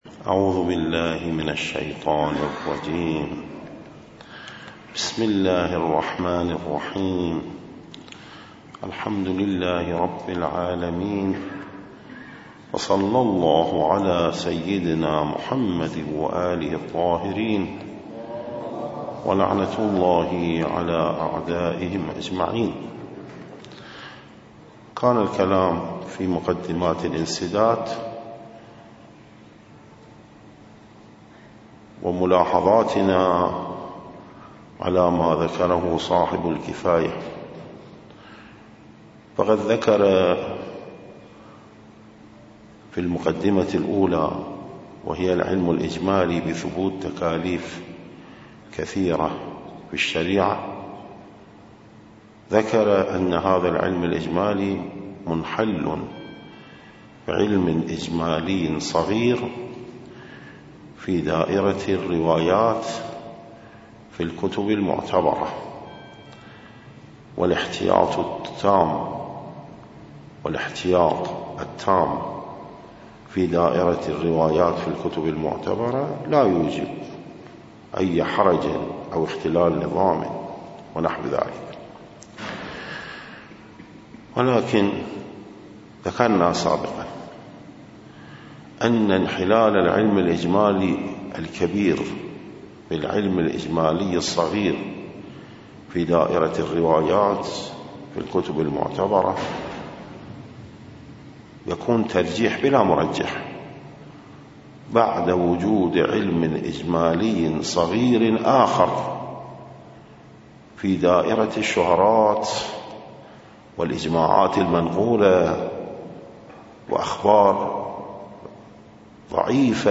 الدرس 22
درس-22.mp3